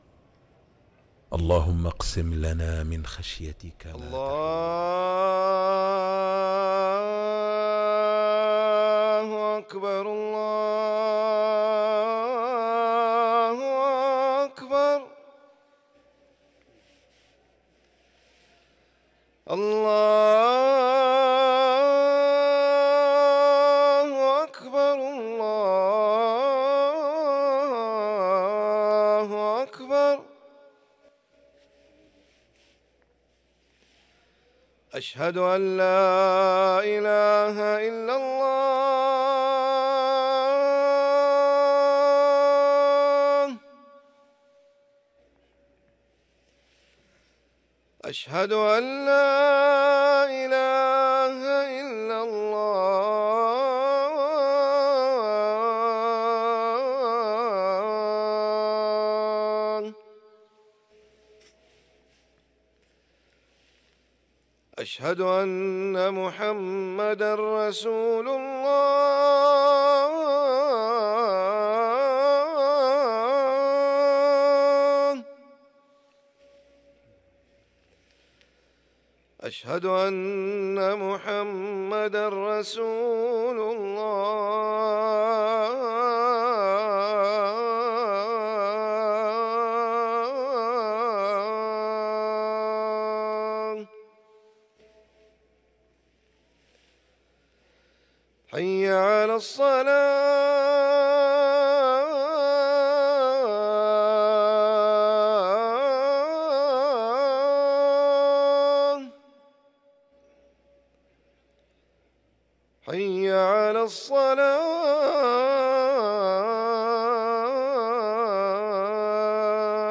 أذان الظهر